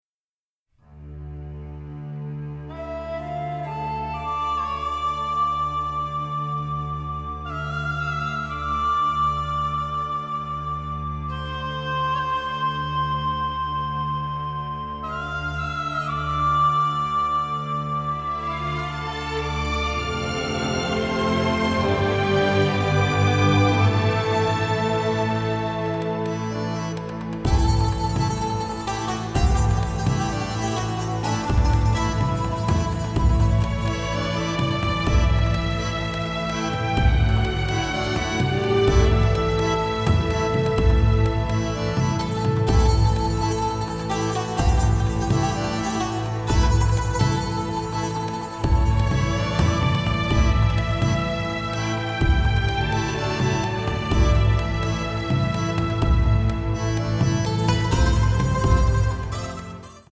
adventure score
Middle Eastern flavor and religious splendor